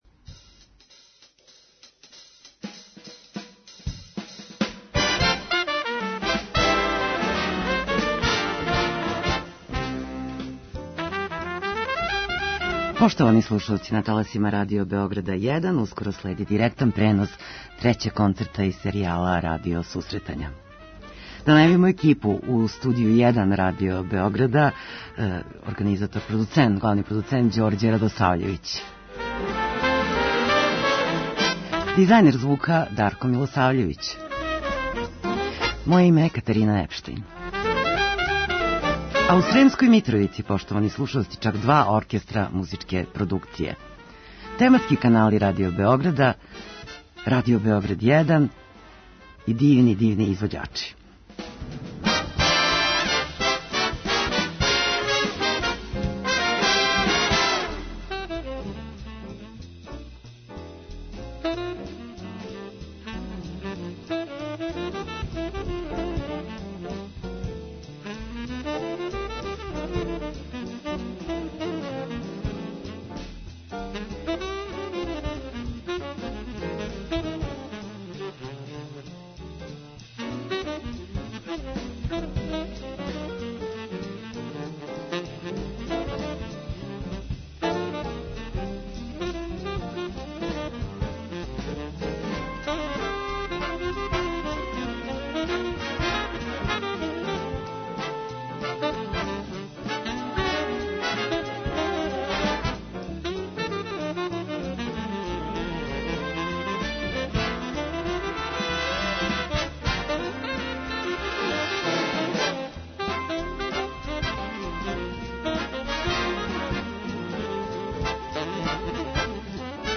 Radio Susretanja, direktan prenos koncerta iz Sremske Mitrovice
Narodni ansambl i Big Bend RTS-a deliće pozornicu na koncertu koji će se održati na Trgu Ćire Milekića u Sremskoj Mitrovici u okviru serije koncerata 'Radio susretanja'.
Ideja koncerta je da se pevači koji su karijeru izgradili u jednom muzičkom žanru, pokažu i kao vrsni izvođači u nekom drugom žanru.
Direktan prenos na Prvom programu Radio Beograda od 20.30.